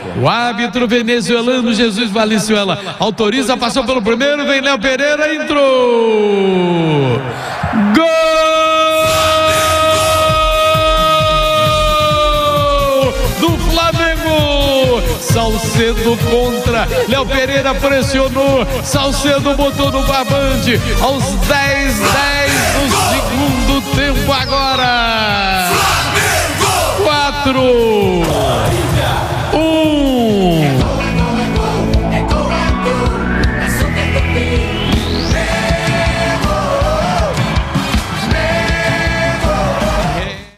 Ouça os gols da goleada do Flamengo sobre o Olimpia na Libertadores com a narração de Garotinho